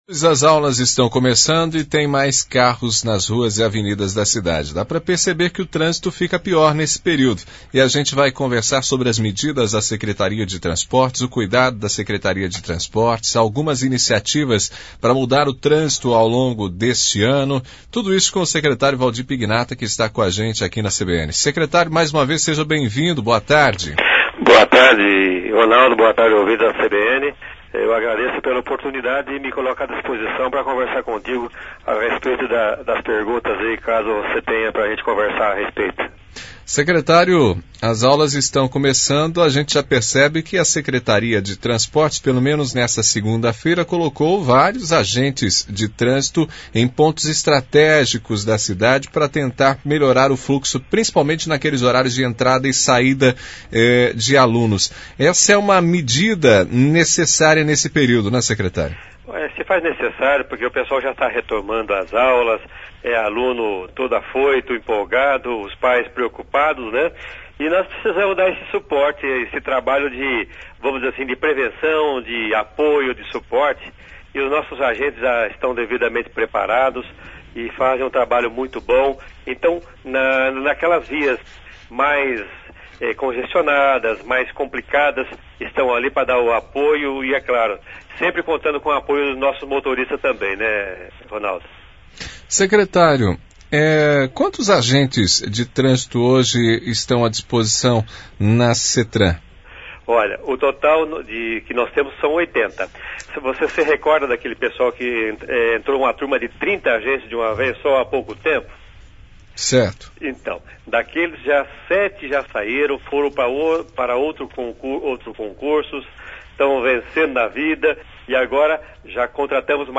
Secretario Valdir Pignara R.N 06-02 AO VIVO.mp3